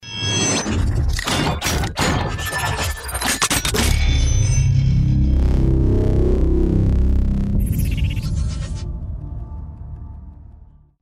На этой странице собраны звуки из вселенной Трансформеров: эффекты трансформации, футуристические боевые режимы, голоса известных автоботов и десептиконов.
Звук трансформеров мобильных для смс сообщений